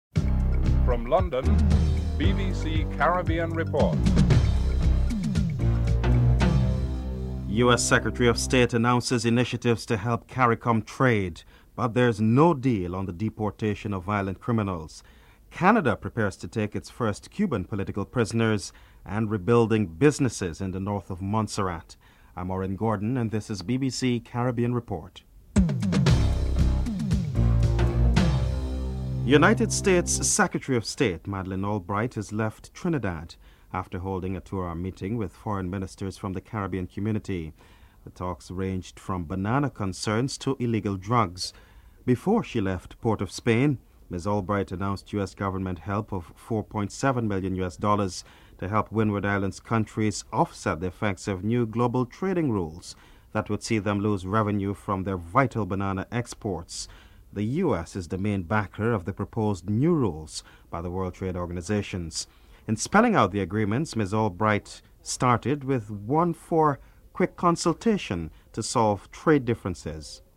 2. US Secretary of State, Madeleine Albright announces initiatives to help CARICOM trade but there is no deal on the issue of deportation of violent criminals to the region. St. Lucia Foreign Minister George Odlum comments on the issue (00:28-03:39)
6. American Bishop Thomas Wenski states that there is an extra willingness on the part of some Cuban Americans to give aid to Cuba following the Pope's visit in January (10:53-12:06)